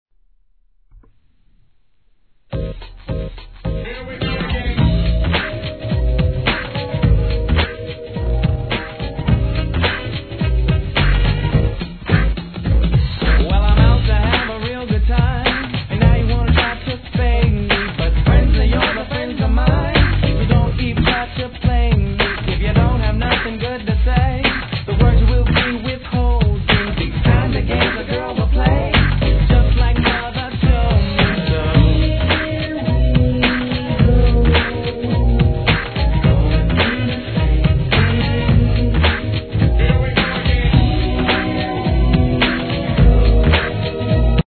1. HIP HOP/R&B
しっかりとした歌唱力と耳に残るメロディーライン、決してミーハーではいけれど、こういうのが安定して売れる盤なんです！！